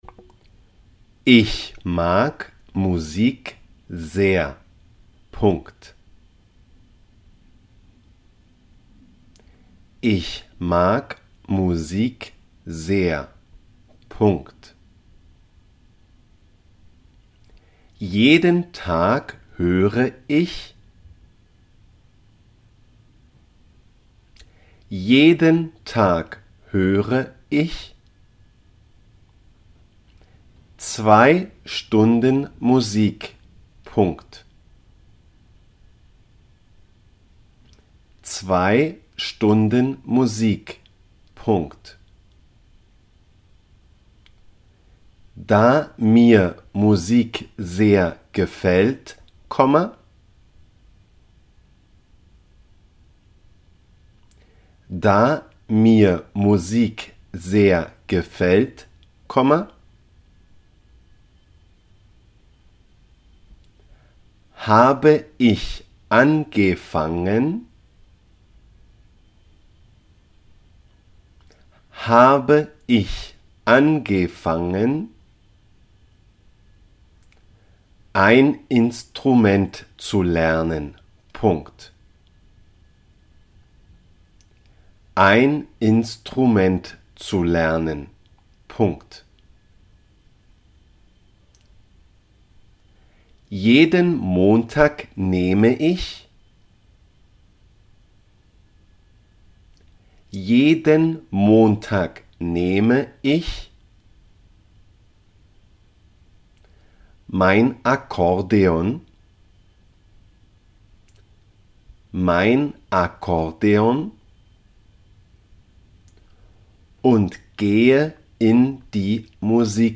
Tocar-un-instrumento-dictado-en-aleman-AprendeAleman.com_-1.mp3